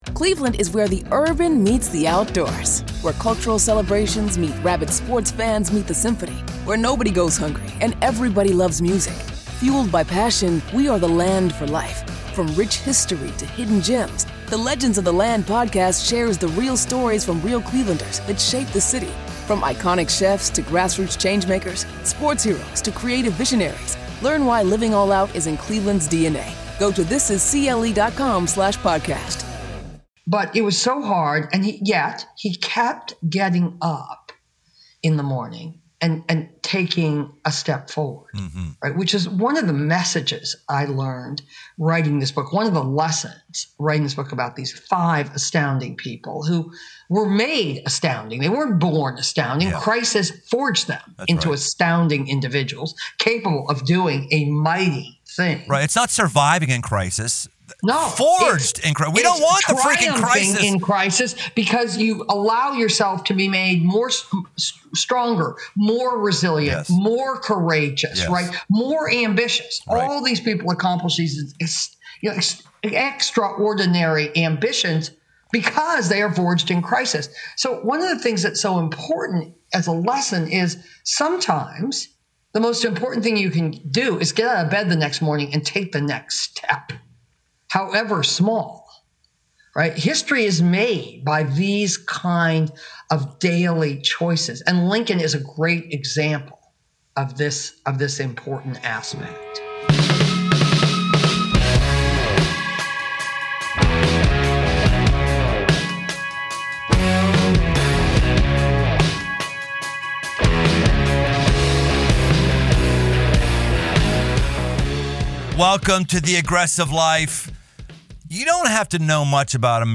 unfiltered conversation